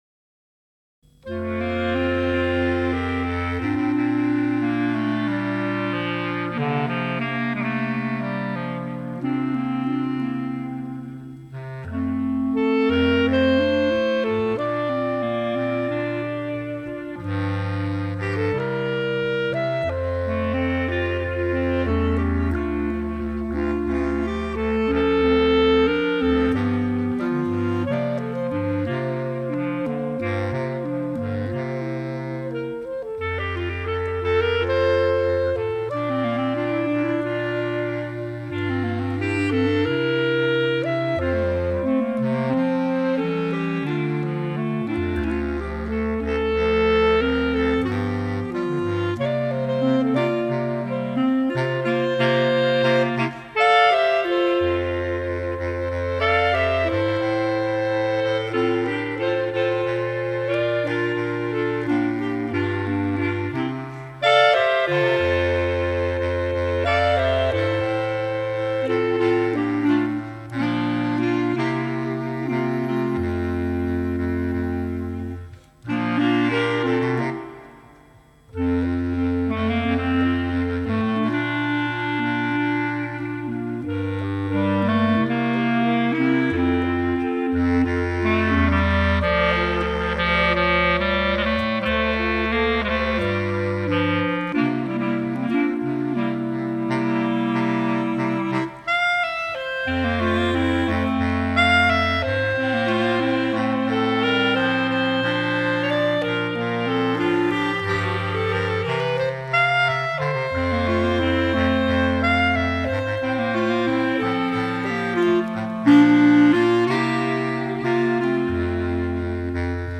Bb Clarinet Range: E1 to G3. Bass Clarinet Lowest Note: E1
1980’s pop